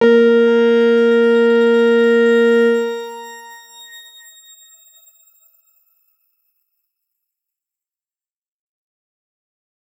X_Grain-A#3-mf.wav